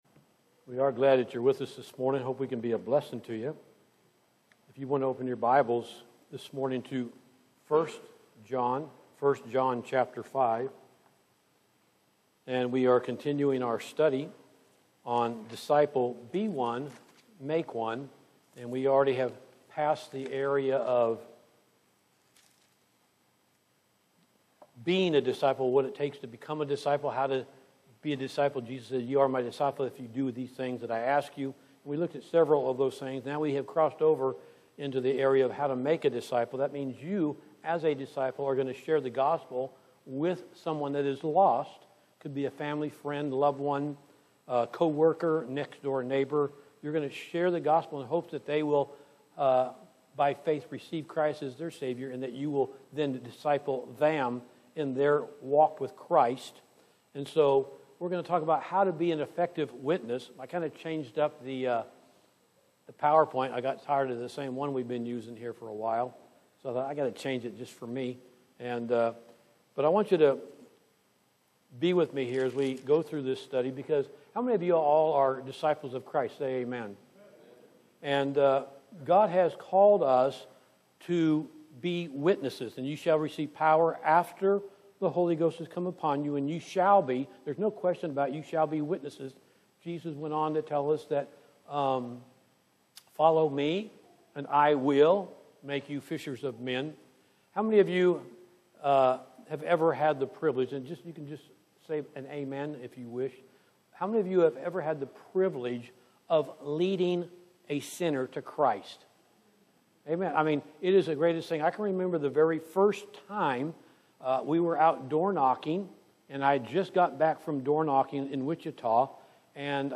Part 2 (John 7:13)From 8/24/25 Sunday AM Service